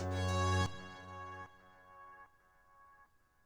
GUnit Synth7.wav